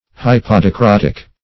Search Result for " hypodicrotic" : The Collaborative International Dictionary of English v.0.48: Hypodicrotic \Hyp`o*di*crot"ic\, Hypodicrotous \Hyp`o*di"cro*tous\, a. (Physiol.) Exhibiting retarded dicrotism; as, a hypodicrotic pulse curve.
hypodicrotic.mp3